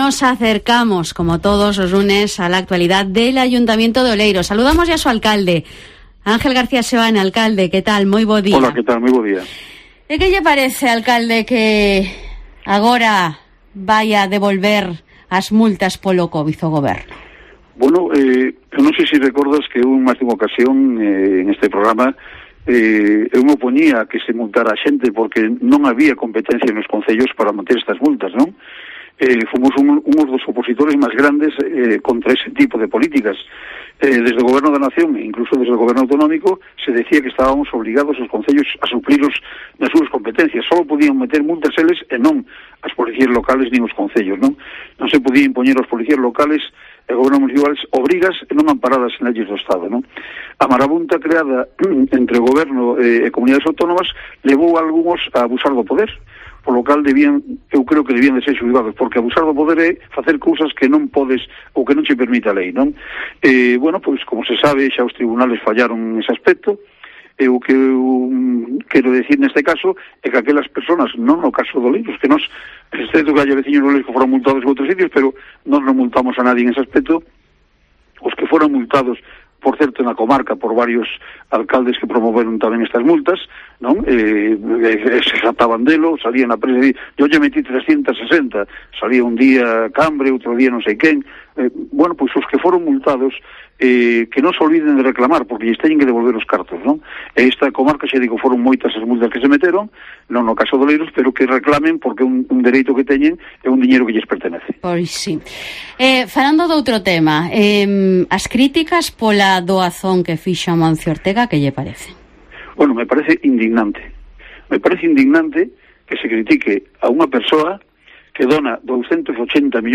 Entrevista a Ángel García Seoane en Mediodía COPE Coruña 25/10/21